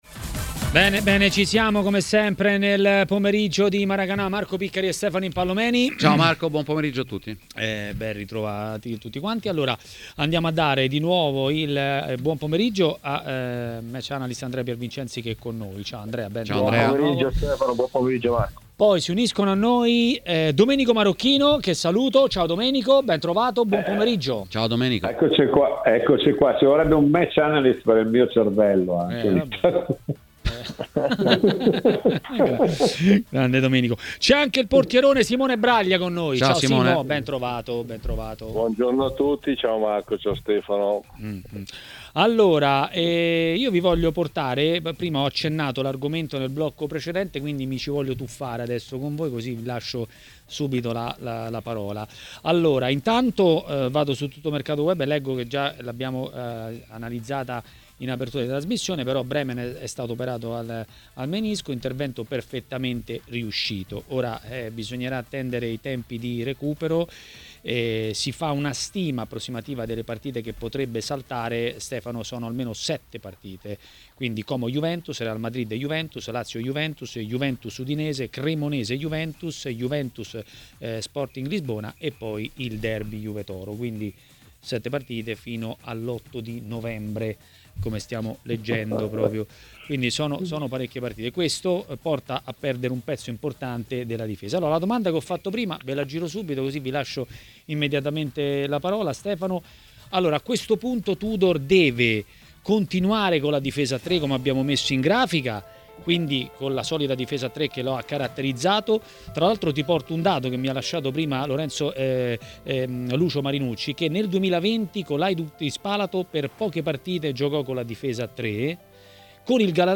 L'ex portiere Simone Braglia è intervenuto a Maracanà, trasmissione di TMW Radio.